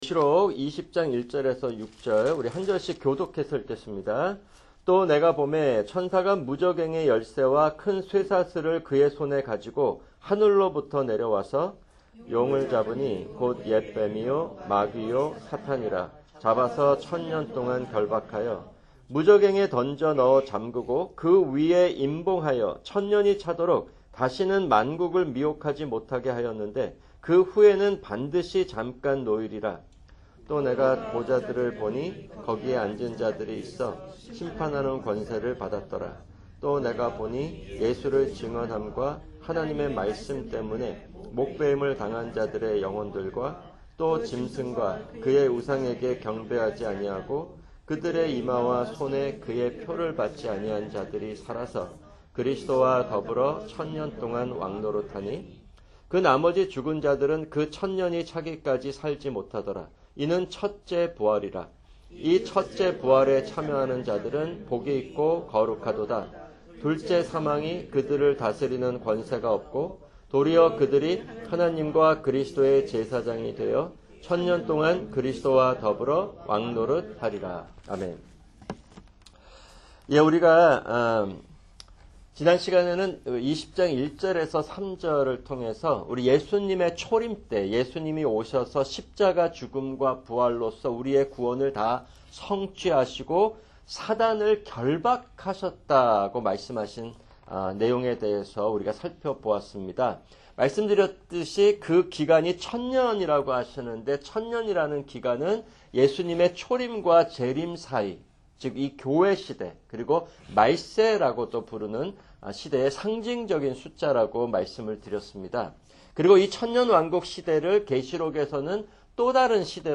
[주일 설교] 민수기 12:1-16